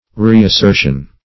Reassertion \Re`as*ser"tion\ (r[=e]`[a^]s*s[~e]r"sh[u^]n)
reassertion.mp3